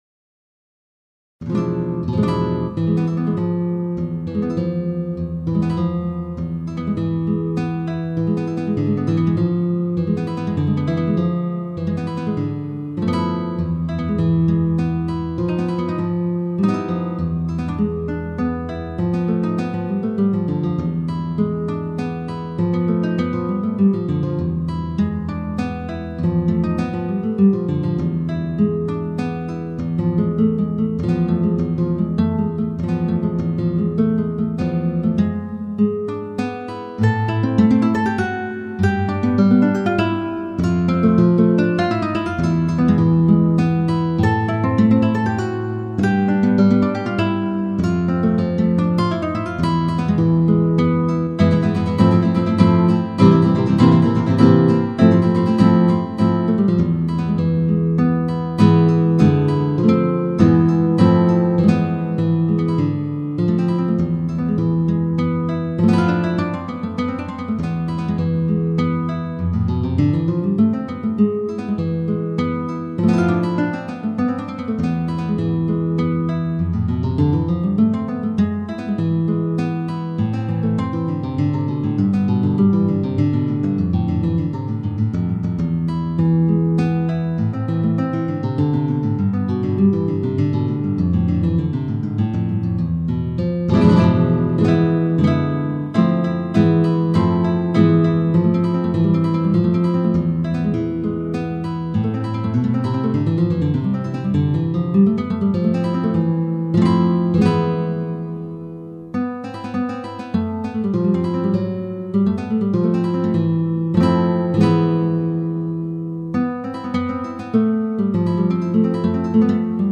Solea (flamenco)